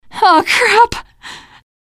ReactionNegative33.mp3